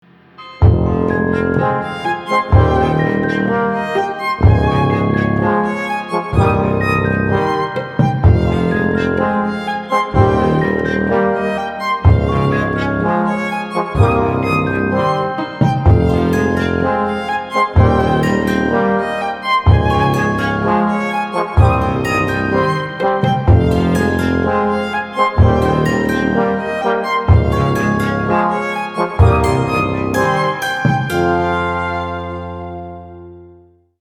• Качество: 192, Stereo
красивые
без слов
скрипка
колокольчики
Gothic Rock
Dark Wave
Neo-Folk